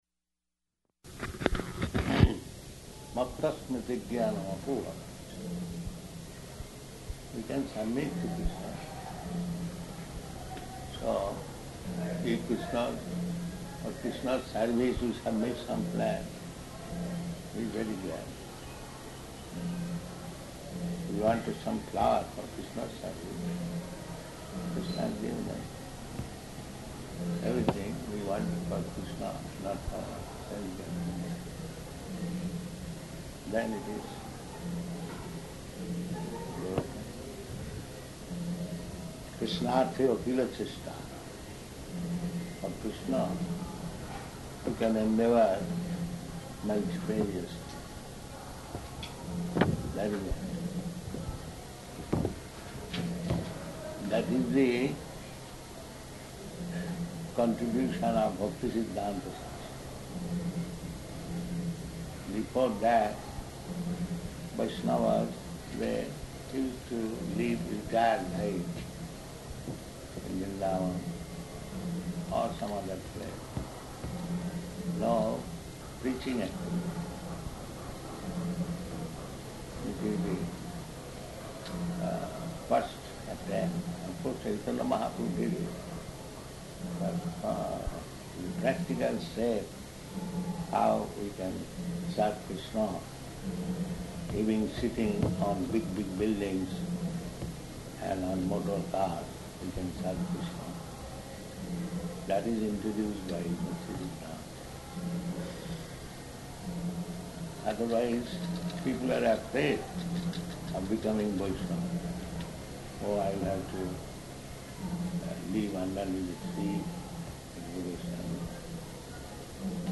Arrival Lecture
Location: Māyāpur